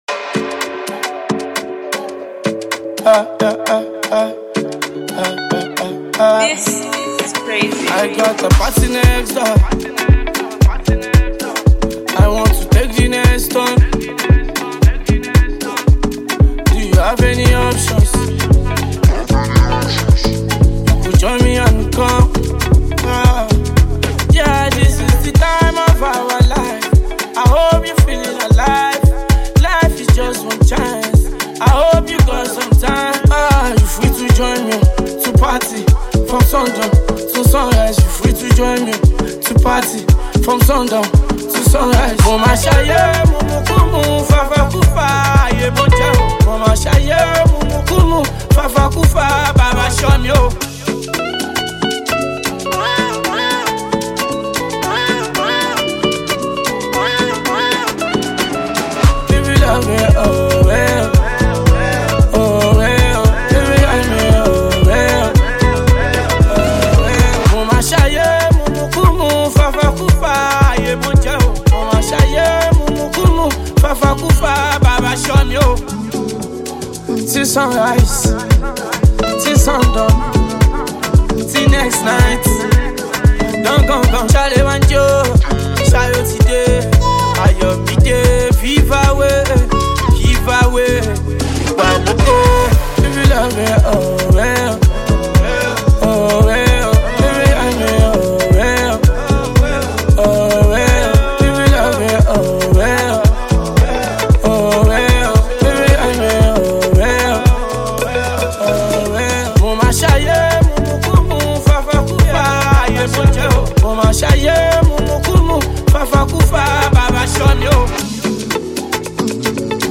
thoughtful Afrobeat track